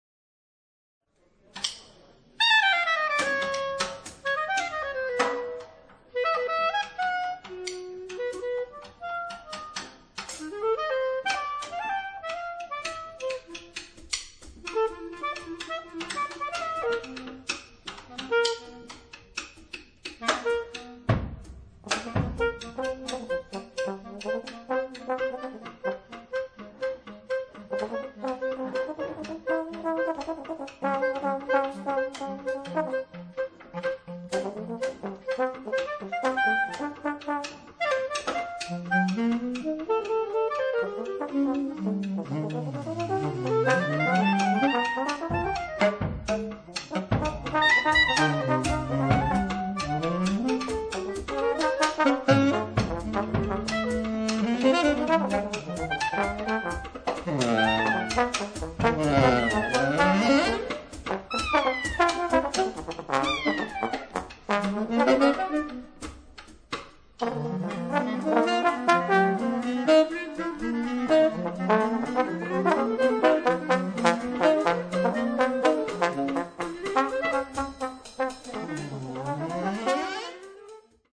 Recorded live June 22, 1996, BIM-huis, Amsterdam